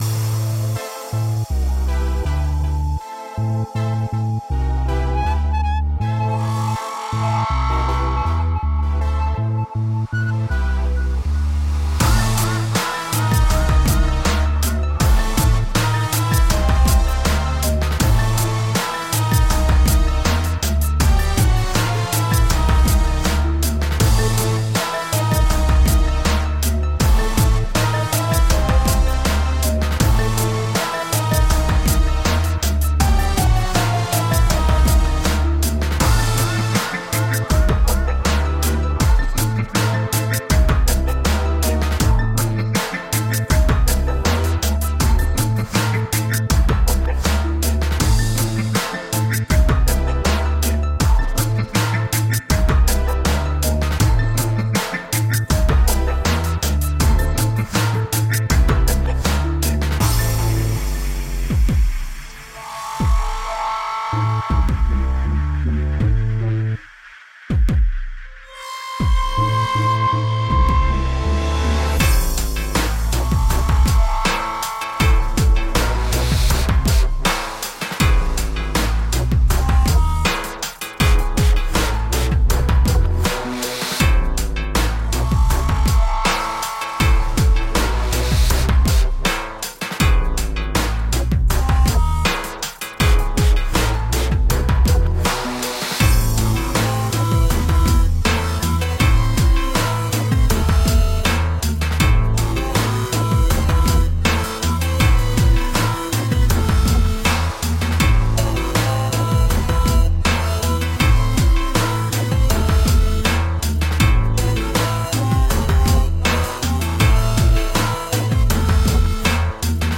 Tagged as: Electronica, Downtempo, Instrumental, Downtempo